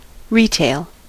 Ääntäminen
Tuntematon aksentti: IPA: /ˈker.to.ɑʔ/